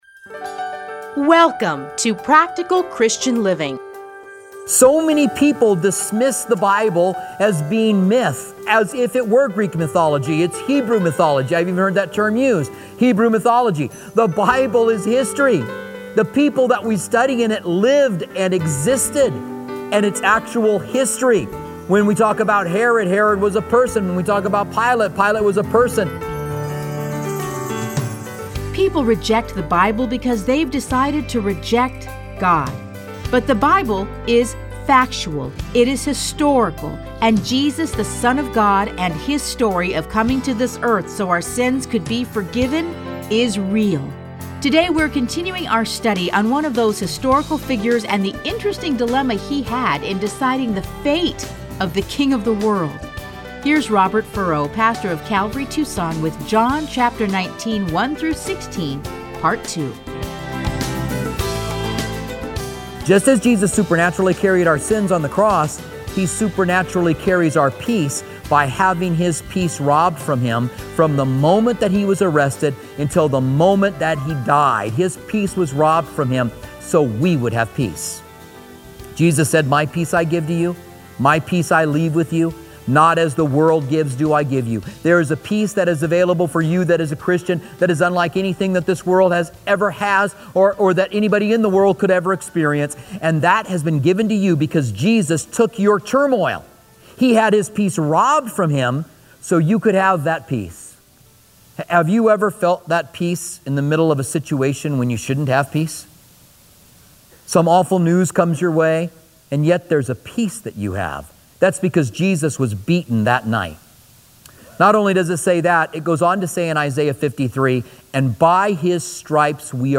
Listen to a teaching from John 19:1-6.